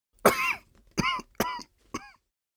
Human, Cough, Male 02 SND54342.wav